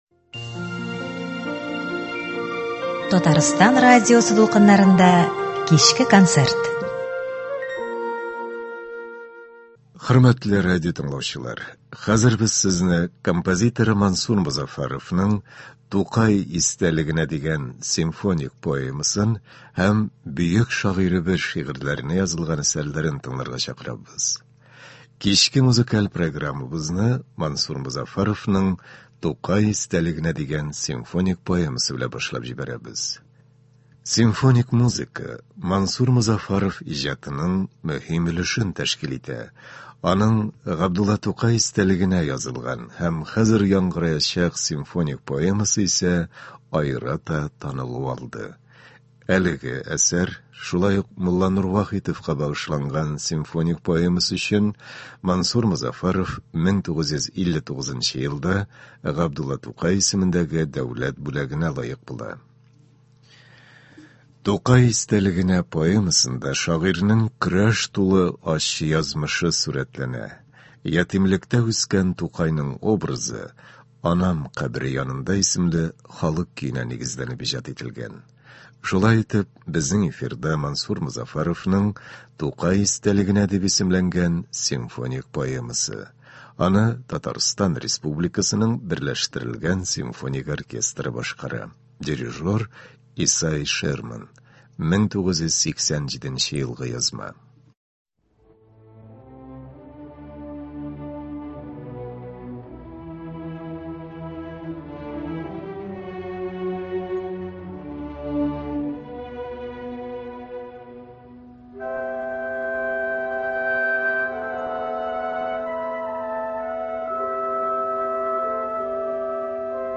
“Тукай истәлегенә”. Симфоник поэма.
Концерт (24.04.24)